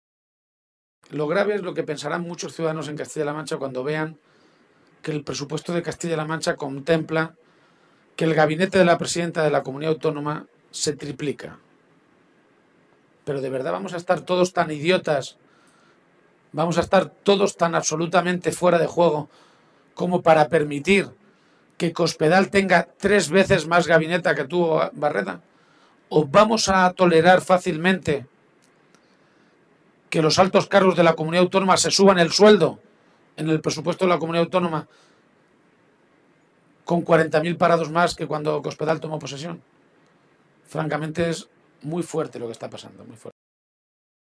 Poco antes del inicio de esa reunión, el secretario general regional, Emiliano García-Page, ha comparecido ante los medios de comunicación para anunciar la aprobación de una resolución que ha llamado “La Resolución de Cuenca”.